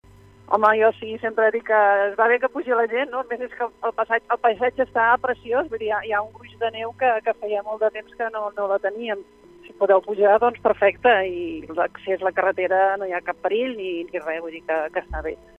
Lídia Bargas, alcaldessa de Prades anima els visitants a anar a Prades Lídia Bargas, alcaldessa de Prades ens explica la situació del municipi